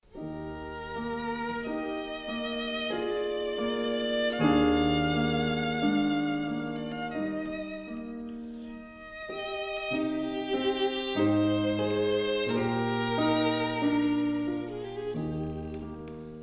• W.A. Mozart: Violin Sonata in Bb, K.454 (Piano+Violin)